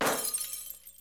Original (non-structured) Glass Smash Sound
glass-smash.aiff